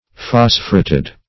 Search Result for " phosphureted" : The Collaborative International Dictionary of English v.0.48: Phosphureted \Phos"phu*ret`ed\ (f[o^]s"f[-u]*r[e^]t`[e^]d), a. (Chem.)